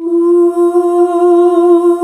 UUUUH   F.wav